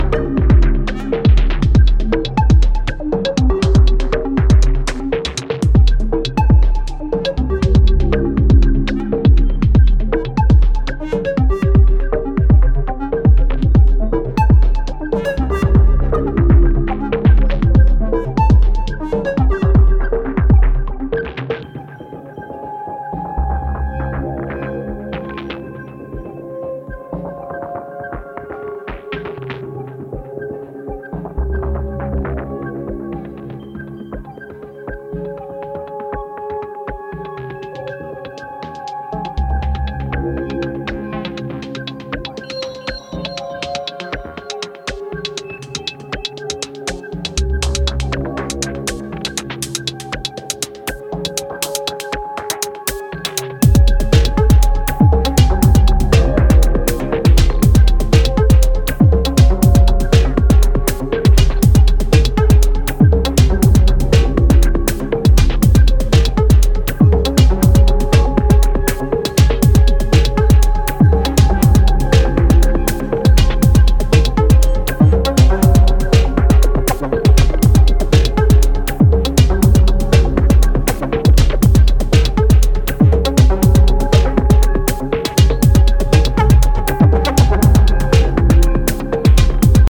重々しく駆動する120BPM